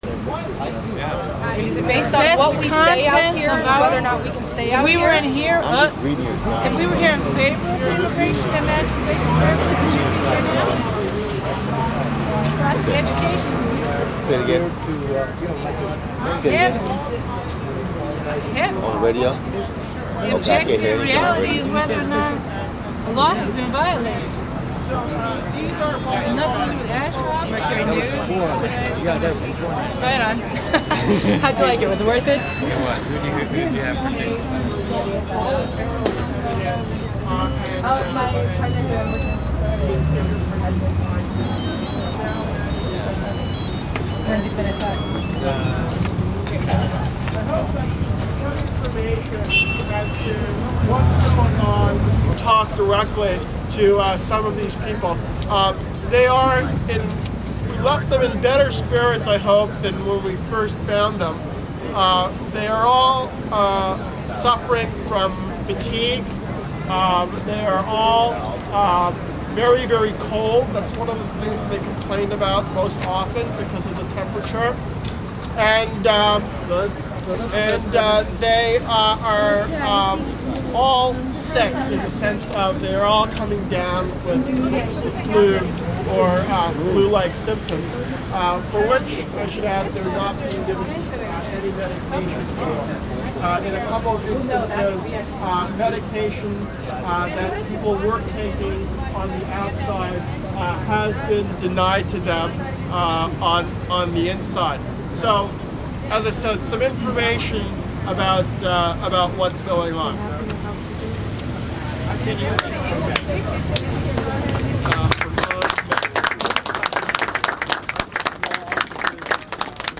copstalk.mp3